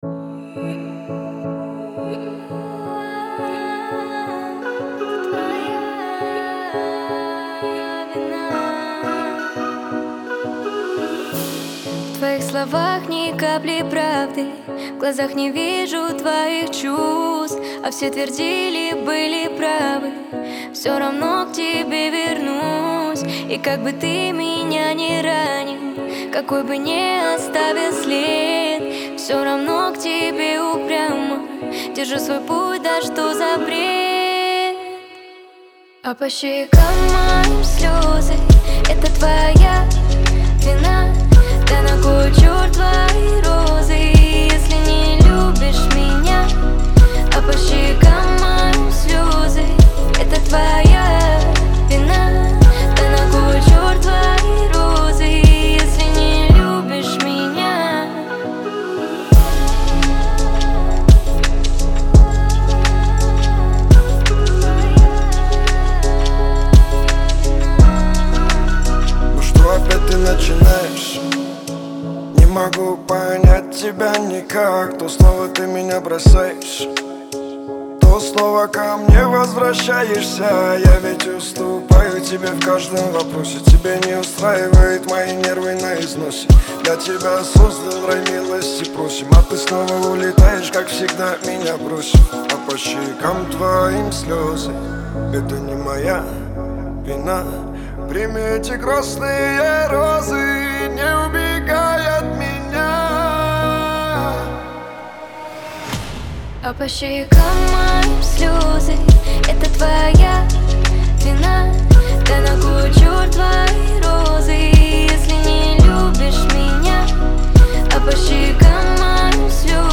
поп-рэп